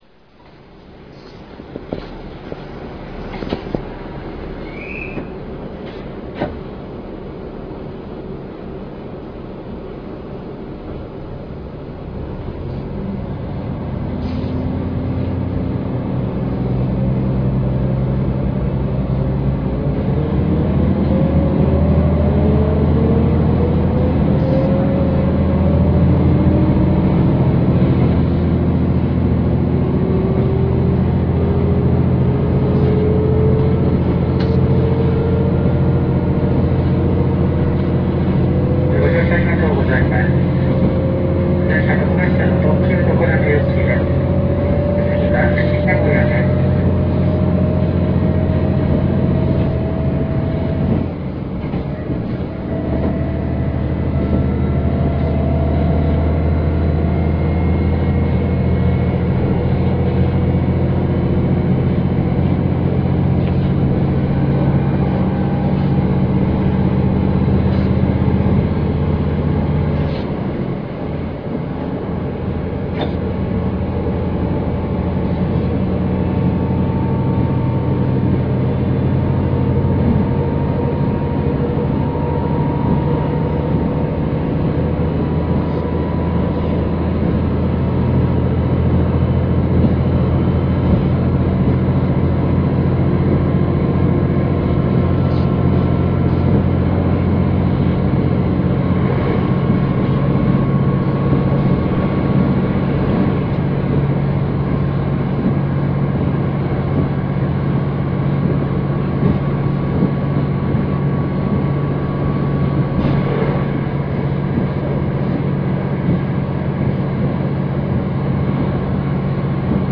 加速音[mt8500a.ra/370KB]
駆動機関：NTA855-R1(355PS)×2